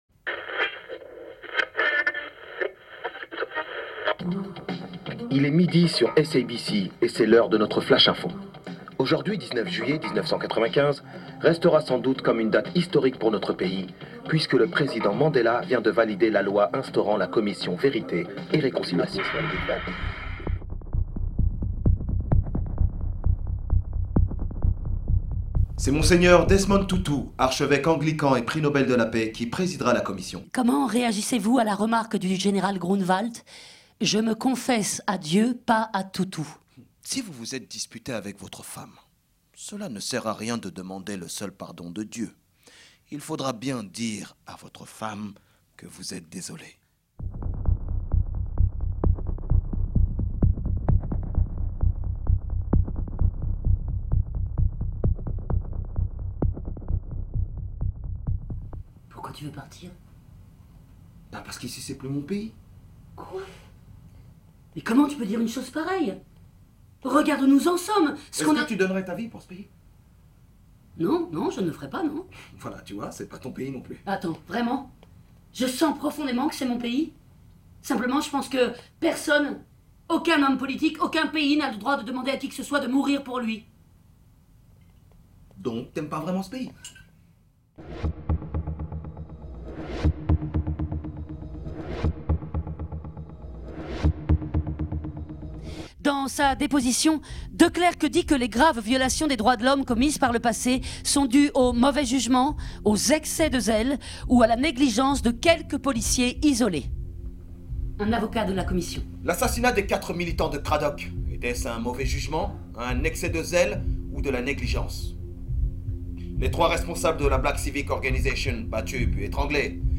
Lecture-spectacle